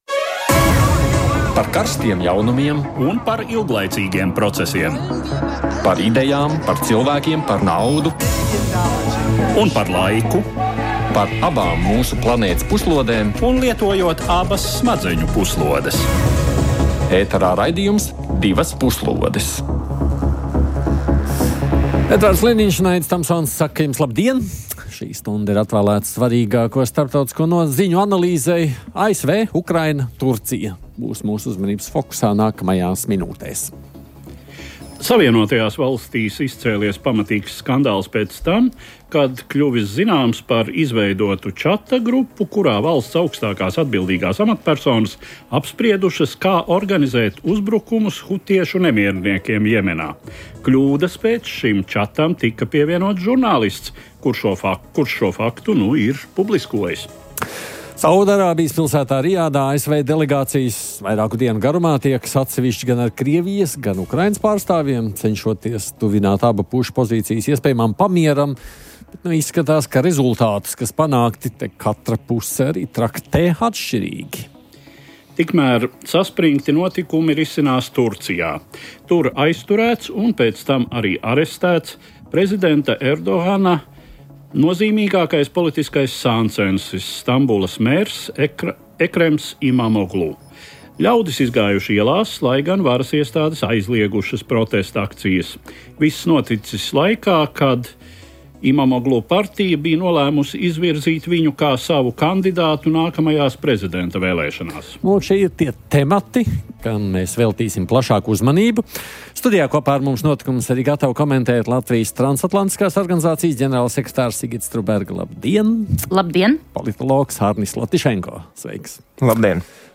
Diskutē